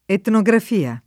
etnografia [ etno g raf & a ] s. f.